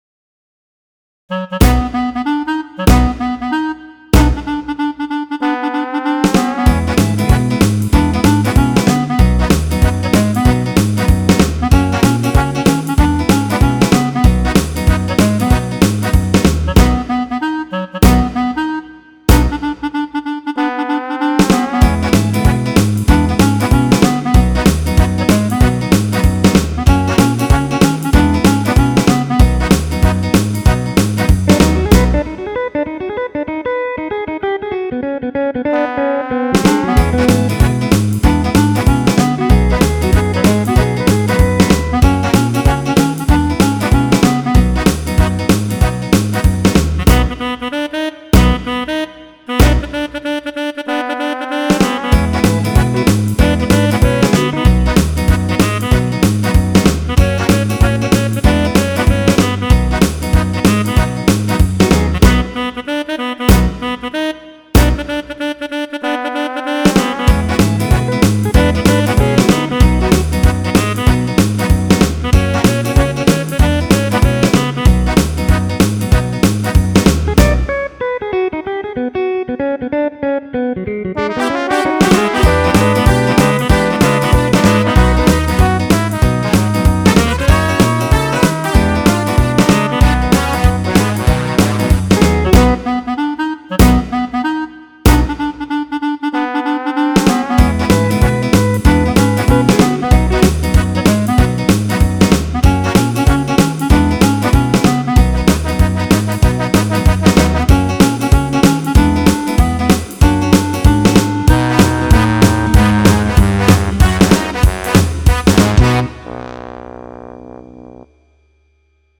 Obviously, these backups would be for male singers only.